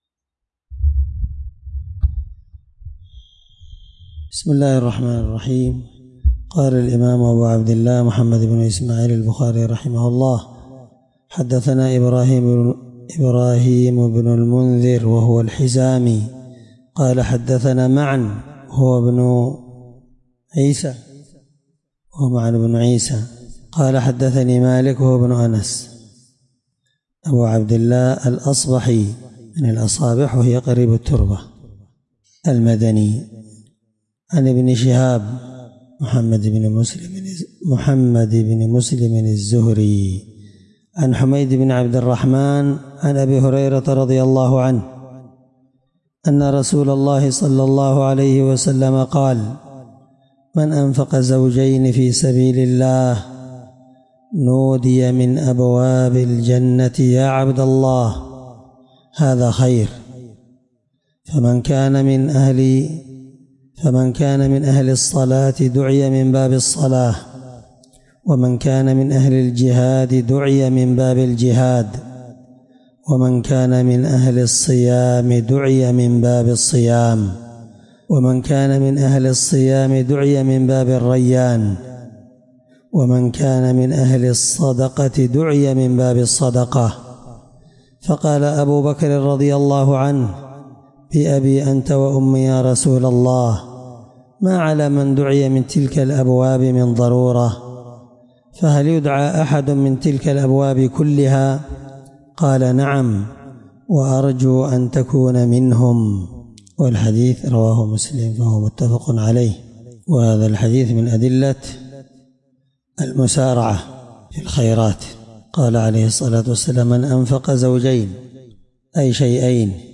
الدرس 5من شرح كتاب الصوم حديث رقم(1897 )من صحيح البخاري